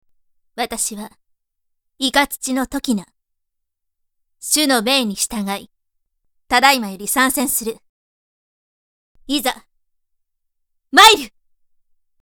【サンプルセリフ】
そこに、透明感のあるお声のイメージもプラスして、凛とした雰囲気で設定してみました。